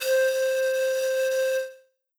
Per farvi un esempio: ho registrato il suono di un flauto che riproduce la nota do.
È mono, è campionato a 44100 hertz e dura circa due secondi; il file pesa 189 kilobyte.
flauto1.wav